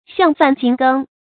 橡饭菁羹 xiàng fàn jīng gēng
橡饭菁羹发音
成语注音 ㄒㄧㄤˋ ㄈㄢˋ ㄐㄧㄥ ㄍㄥ